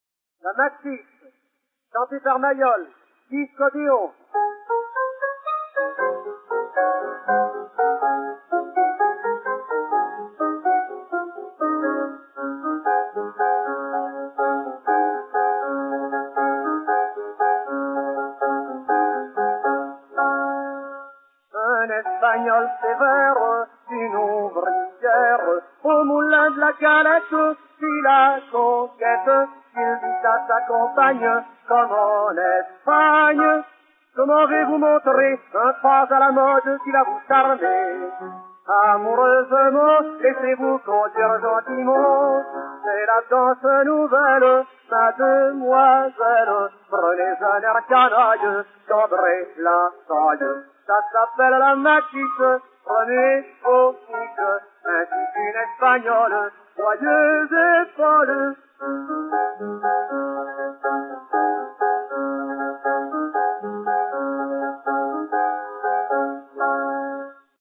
Пластинка начала 1906 года